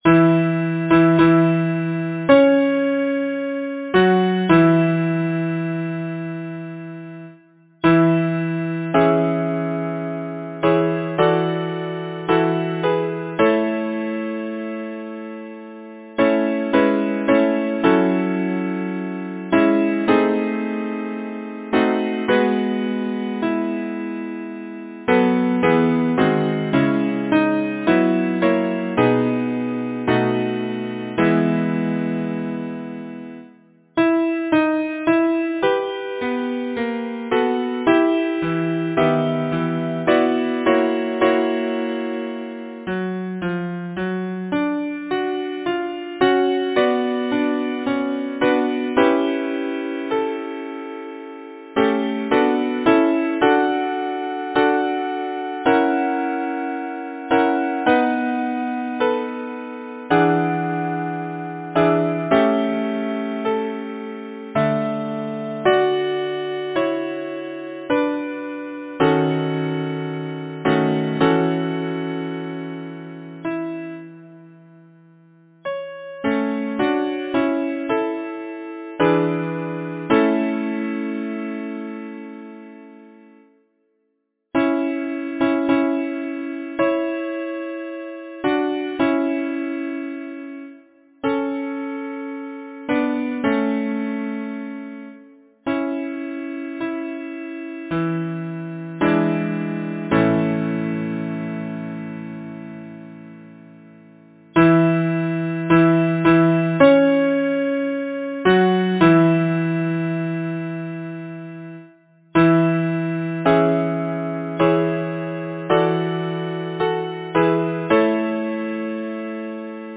Title: Come to me, gentle sleep Composer: Frederic Hymen Cowen Lyricist: Felicia Hemans Number of voices: 4vv Voicing: SATB Genre: Secular, Partsong
Language: English Instruments: A cappella